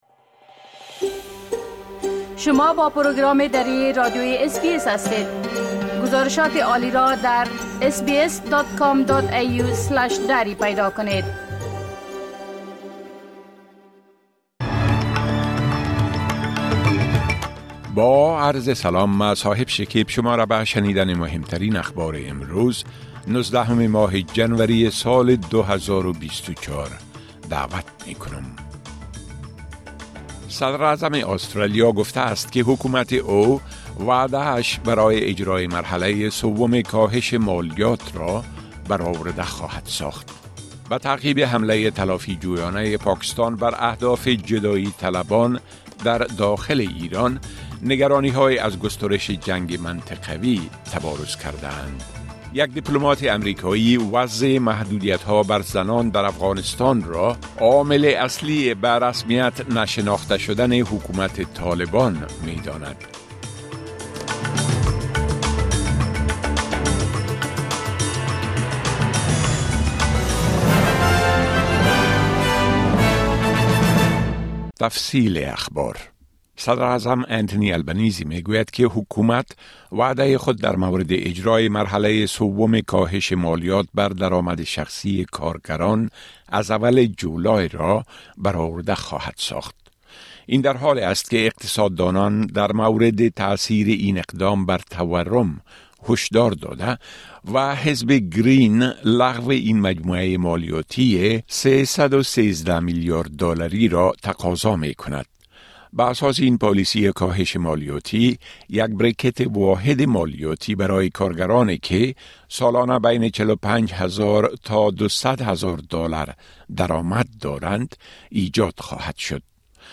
گزارش رويدادهاى مهم اخير از برنامۀ درى راديوى اس بى اس
گزارش رويدادهاى مهم اخير به زبان درى از راديوى اس بى اس را در اينجا شنيده مى توانيد.